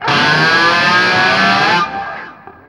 DIVEBOMB 6-L.wav